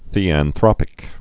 (thēăn-thrŏpĭk) also the·an·throp·i·cal (-ĭ-kəl)